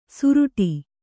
♪ suruṭi